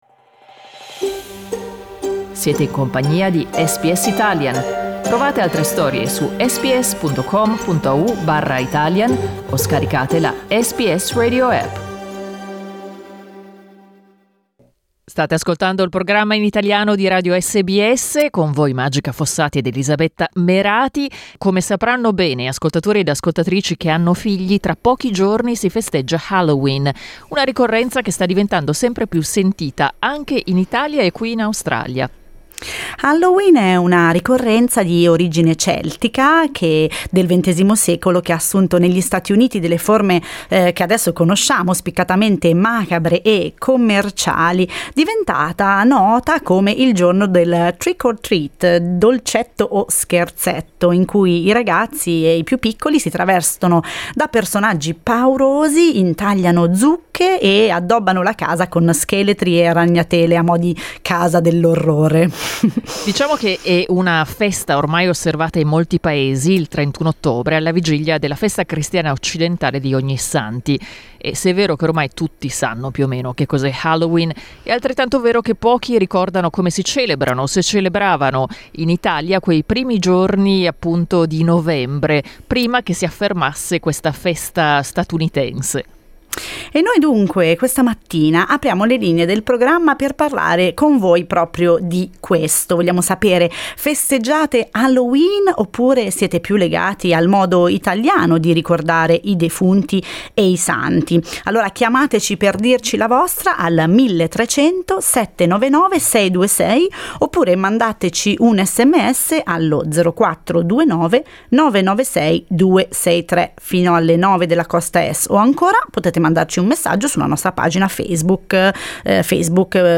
Alcuni ascoltatori ed ascoltatrici sono intervenuti durante il nostro programma questa mattina per non dimenticare alcune delle tradizioni tipiche italiane che osservavano da piccoli per celebrare queste giornate.